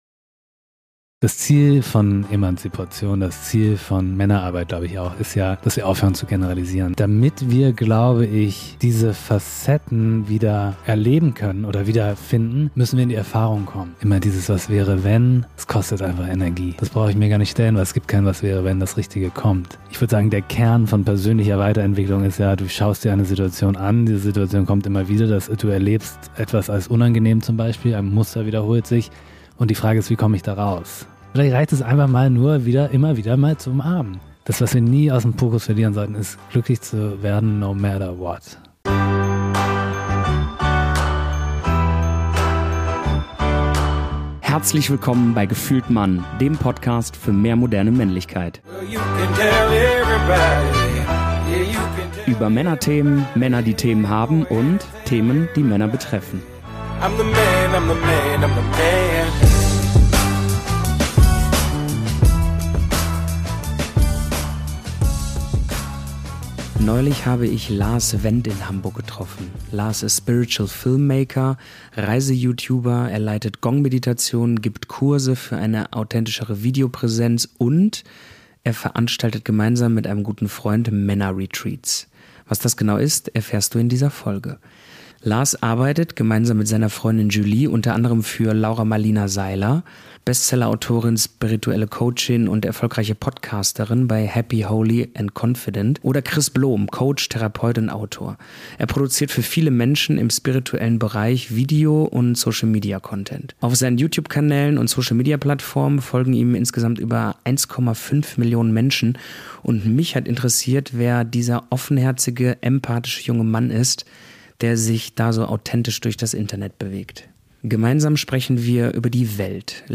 Denn hier, in diesem gemütlichen Ambiente, durften wir das Gespräch aufnehmen.